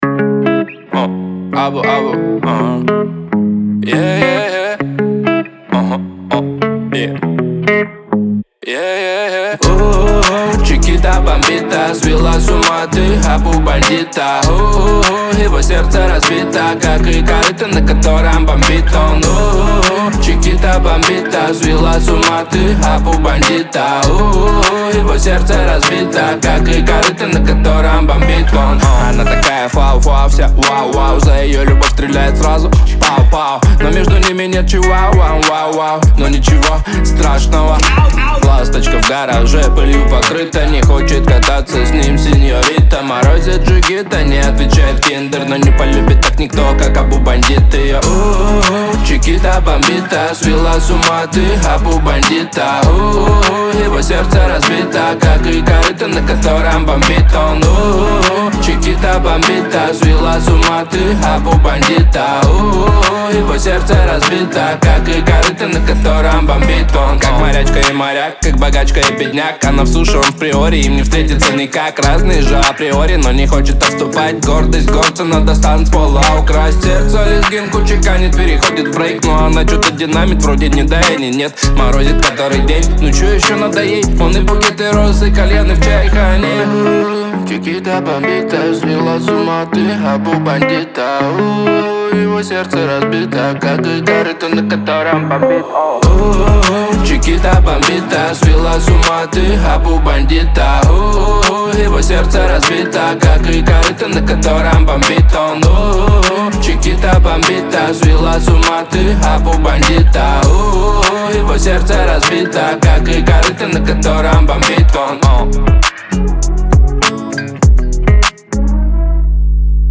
яркими ритмами и запоминающимися мелодиями